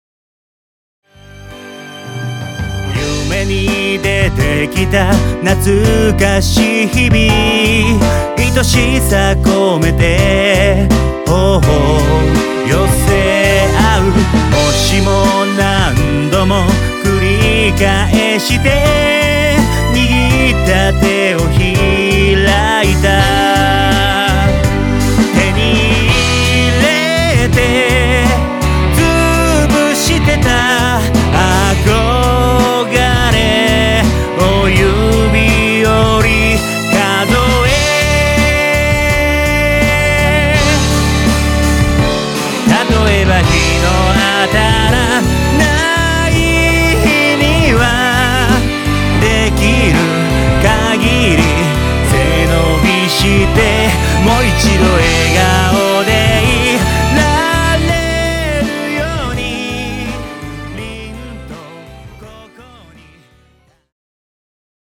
独自に厳選を重ねた8曲を怒涛のロックアレンジ！
ヴォーカル